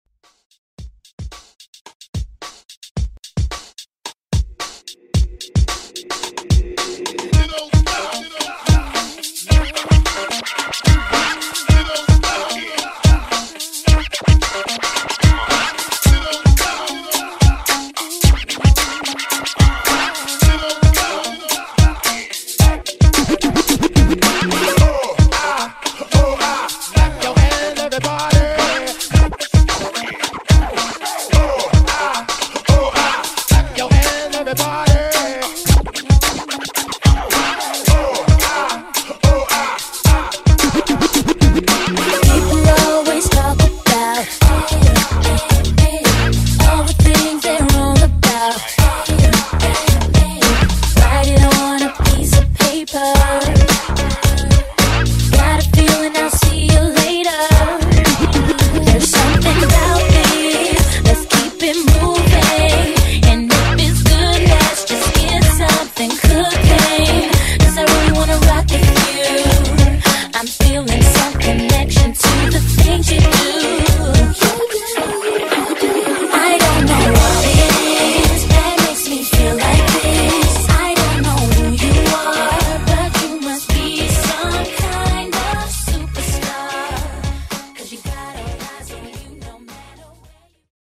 Pop Party Breaks Re-Drum